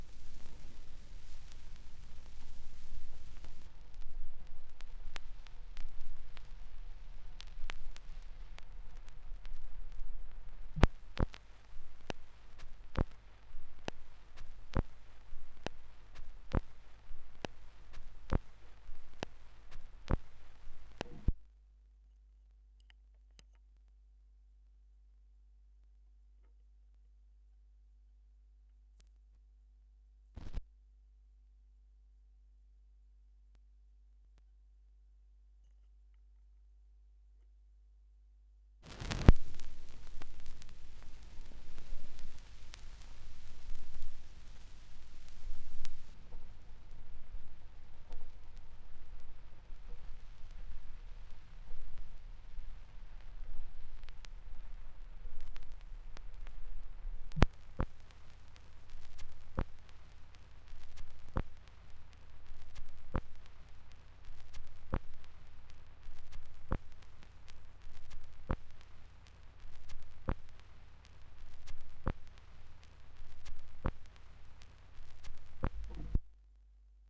2017 Schallplattengeräusche (3)
Leere Phrasen (Leerstellen auf Schallplatten, die digitalisiert werden.)
Digitalisierung: Thorens TD 165 Special, Orthophon Pro System, Tascam HD-P2 (24 Bit, 48 KHz), Audacity